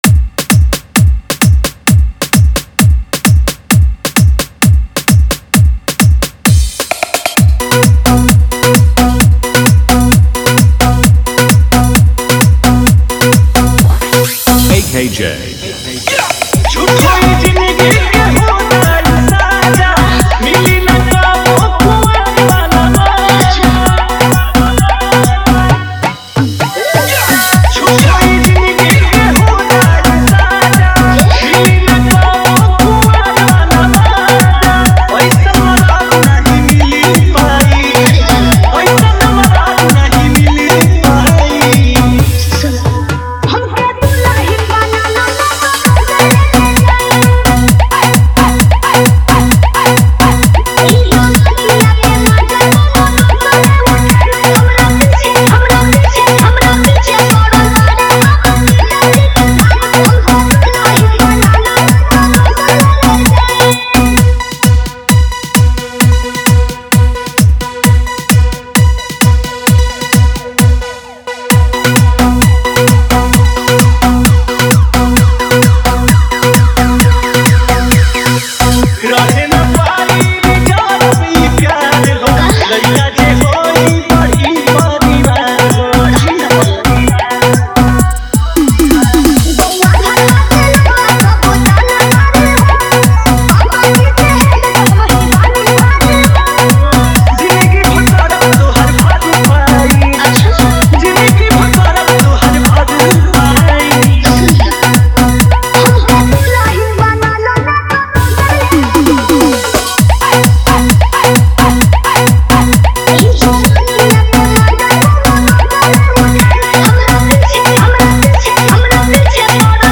Category : Hindi Remix Dj Songs